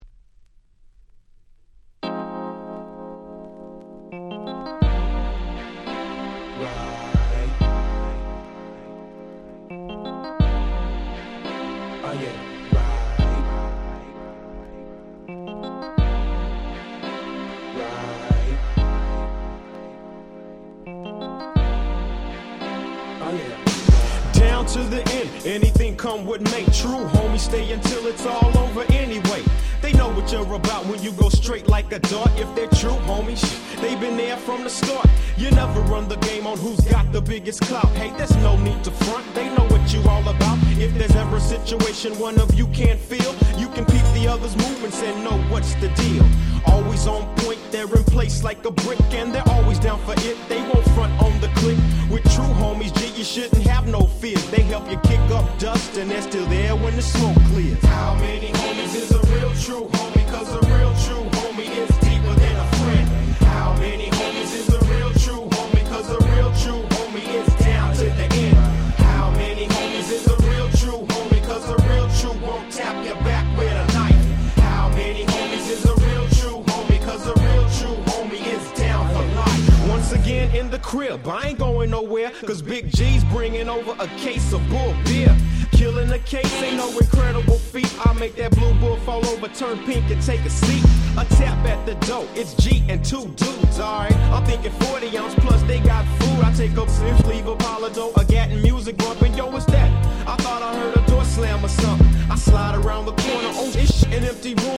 94’ Super Nice 90’s Hip Hop !!
Soulネタでまったりとした